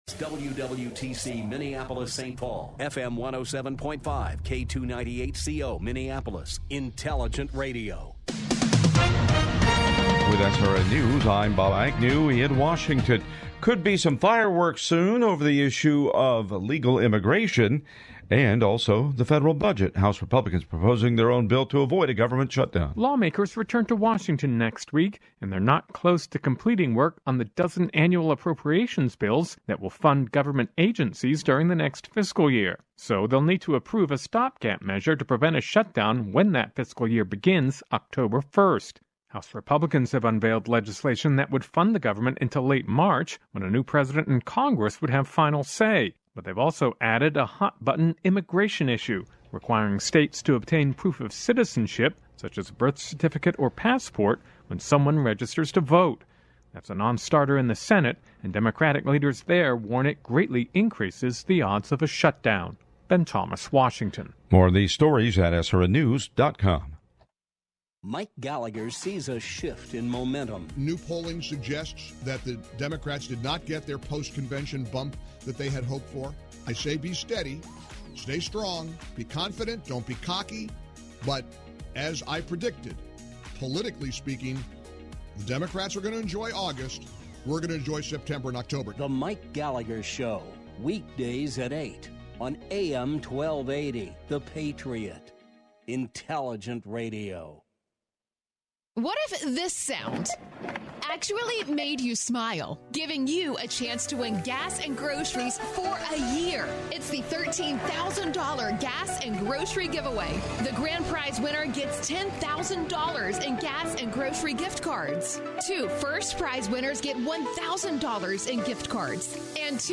Get ready for a fascinating conversation